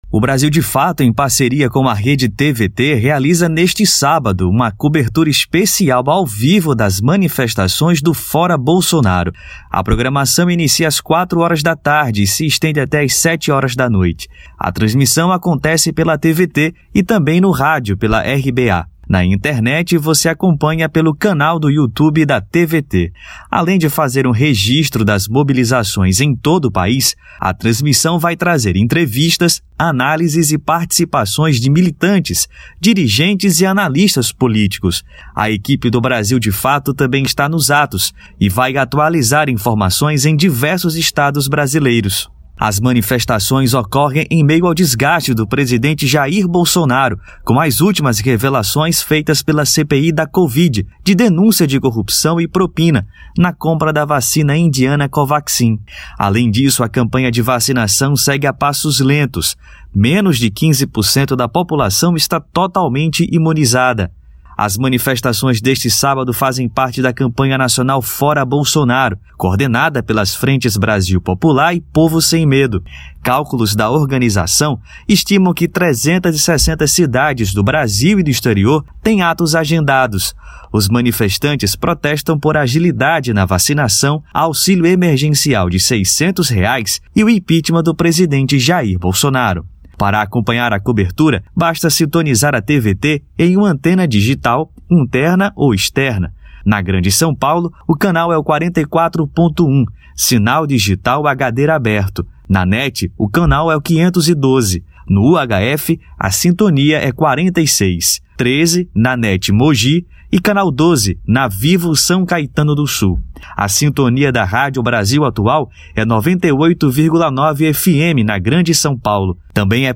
Brasil de Fato e TVT repercutiram ao vivo as manifestações contra Bolsonaro; assista
Além de fazer um registro das mobilizações em todo o país, a transmissão trará entrevistas, análises e participações de militantes, dirigentes e analistas políticos.